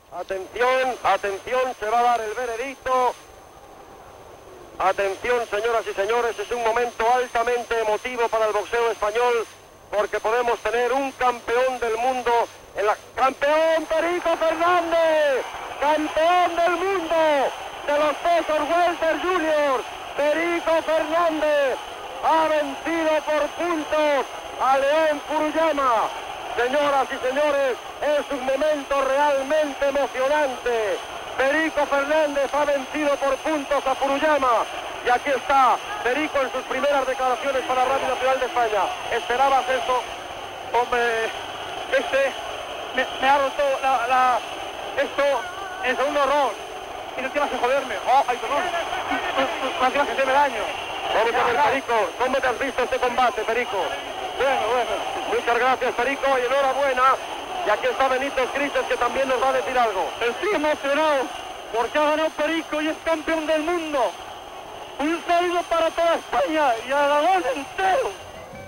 Final del combat de boxa entre Perico Fernández i Lion Furuyana. Perico es proclama campió mundial del pes super lleuger. Declaracions del boxejador
Esportiu